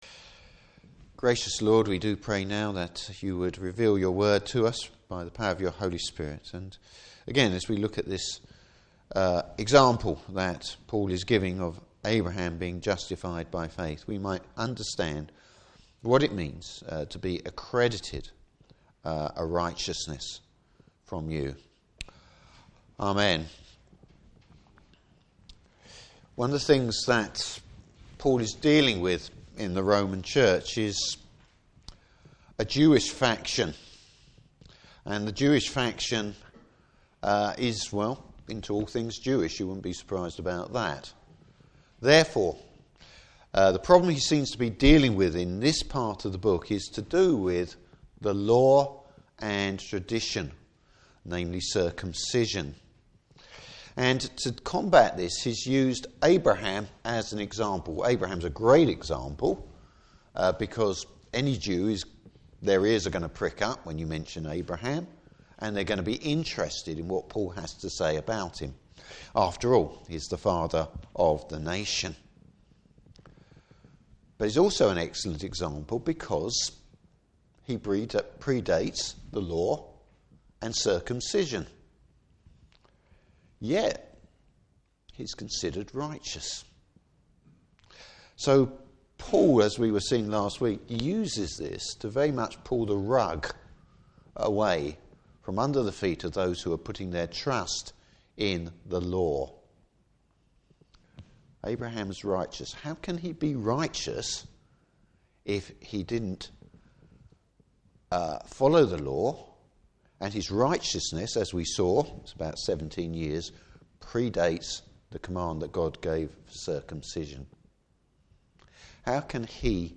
Service Type: Morning Service The only credit you don’t have to repay!